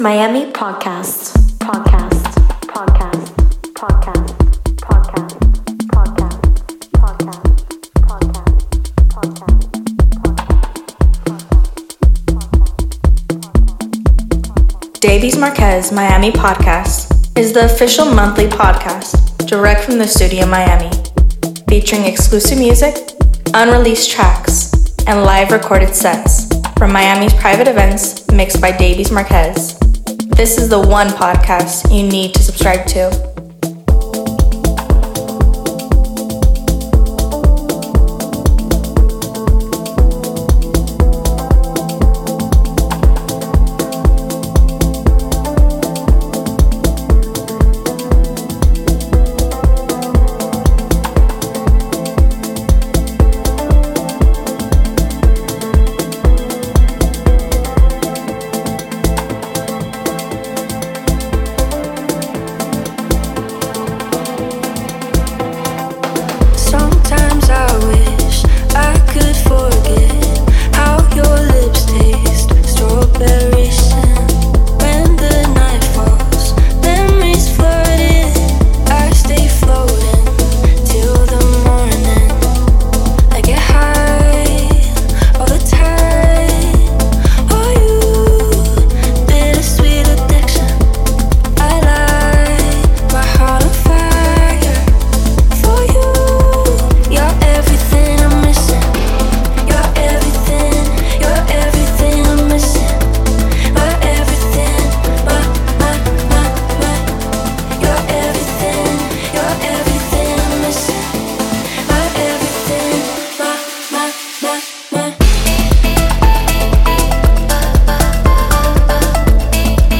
Set Recorded at PE Miami Beach December 2024